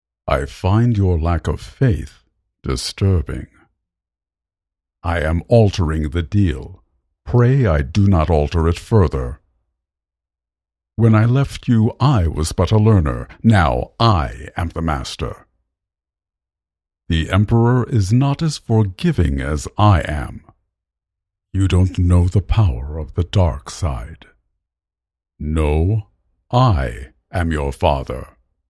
baritone Male Voice Over Talent
I can offer both an authoritative, baritone, or a more youthful, upbeat, near-tenor voice.
0912Darth_Vader_Impression__Final_.mp3